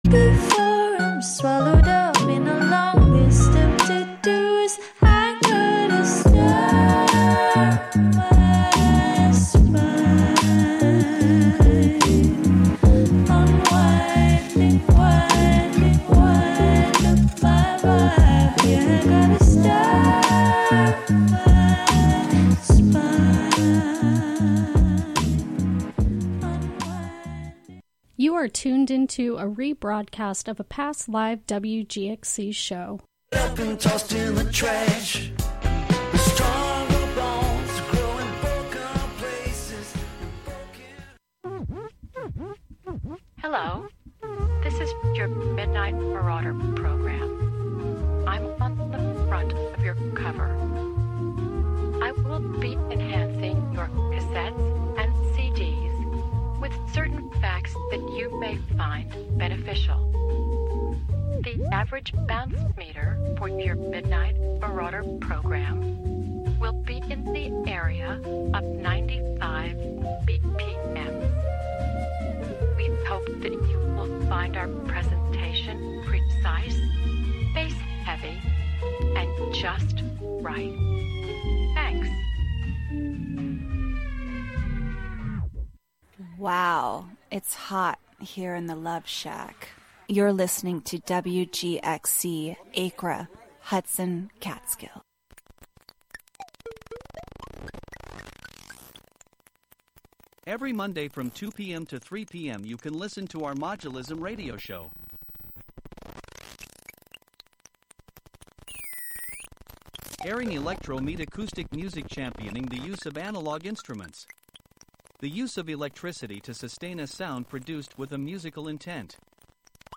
It also amplifies Indigenous music traditions to bring attention to their right to a sustainable future in the face of continued violence and oppression. We will explore the intersection of acoustic ecology, musical ethnography, soundscape studies, and restorative listening practices from the perspective of Indigenous musical TEK (Traditional Ecological Knowledge).